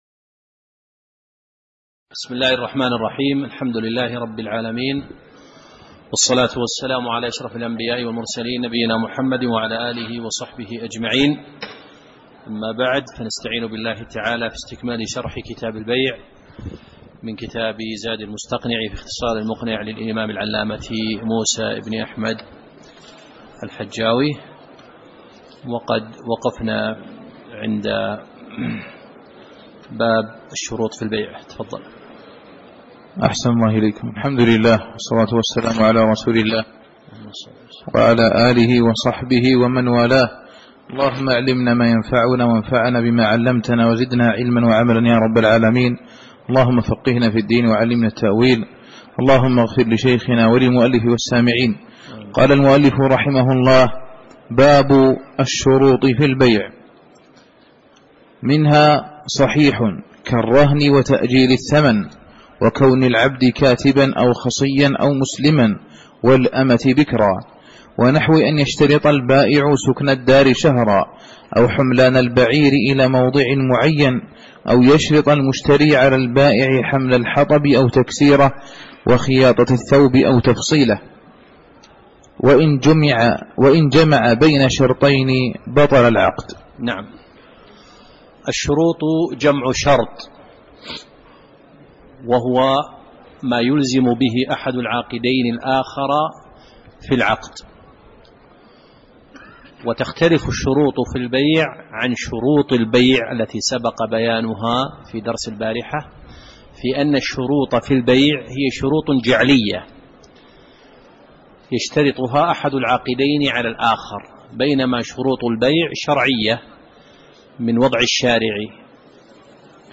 تاريخ النشر ١١ جمادى الآخرة ١٤٣٨ هـ المكان: المسجد النبوي الشيخ